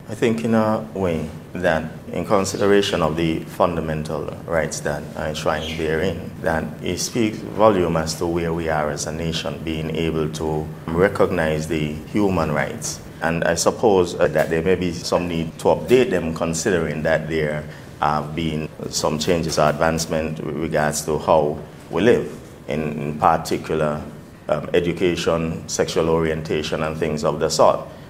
How does St. Kitts and Nevis’s constitution stand after 40 years of change in human society? This topic was discussed during a Panel Discussion Series being held in celebration of the nation’s 40th year of Independence.
Here is Premier of Nevis, Hon. Mark Brantley: